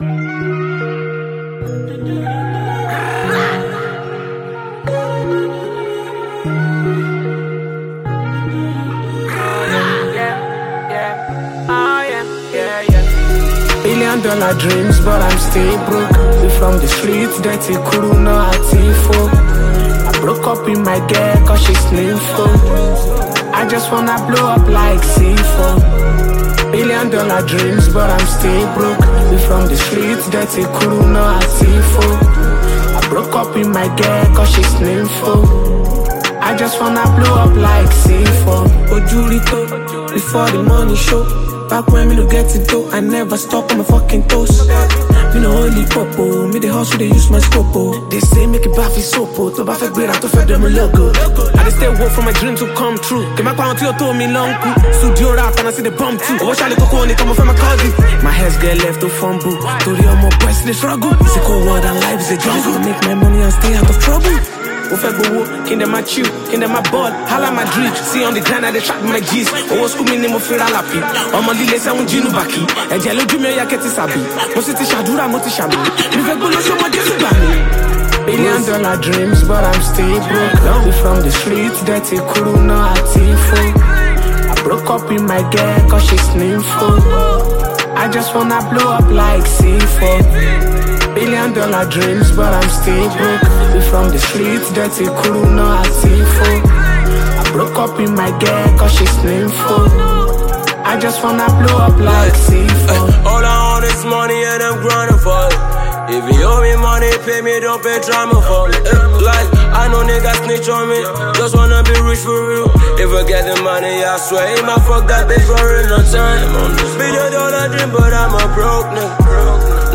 Nigerian rap artist
Afrobeats